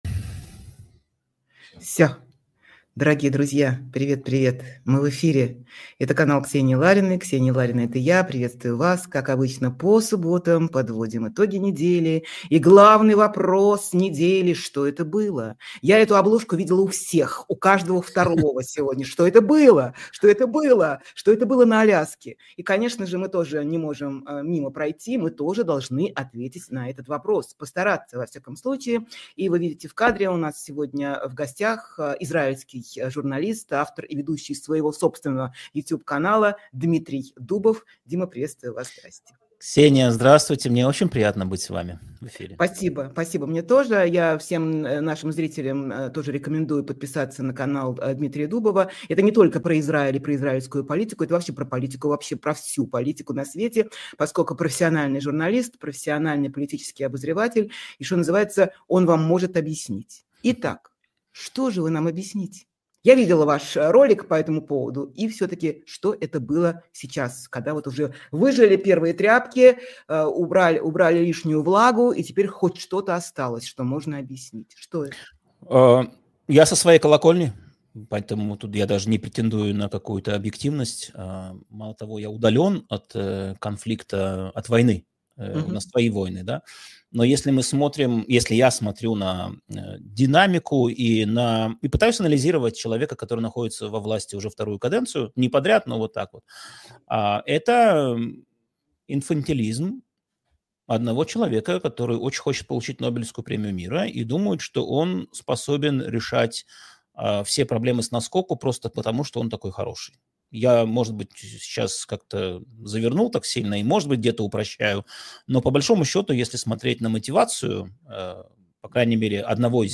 Эфир ведёт Ксения Ларина
израильский журналист, политический обозреватель